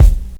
INSKICK08 -R.wav